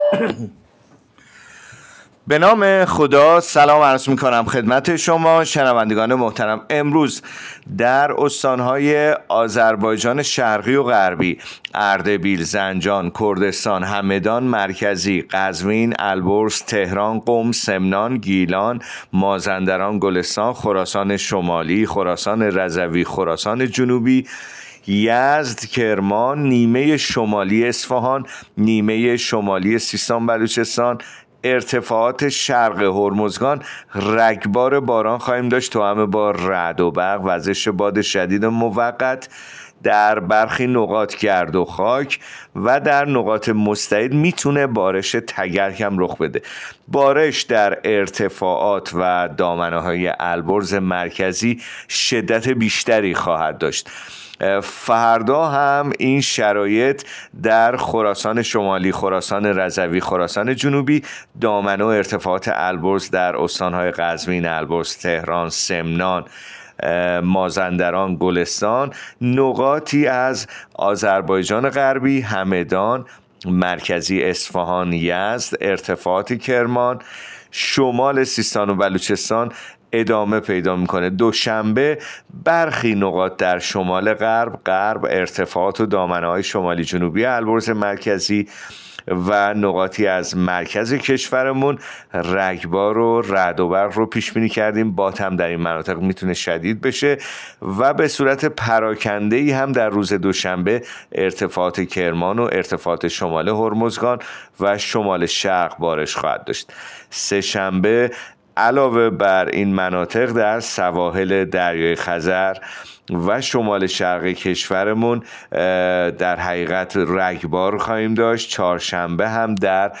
گزارش رادیو اینترنتی پایگاه‌ خبری از آخرین وضعیت آب‌وهوای ۱۷ خرداد؛